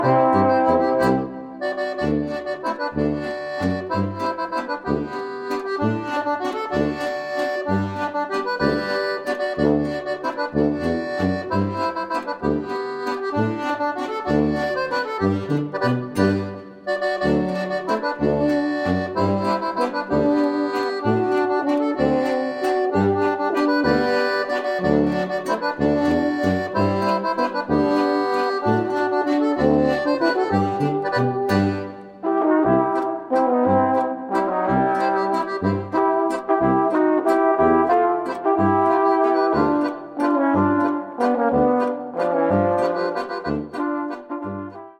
Gattung: Volksmusik natürlicher und ursprünglicher Art
Besetzung: Volksmusik/Volkstümlich Weisenbläser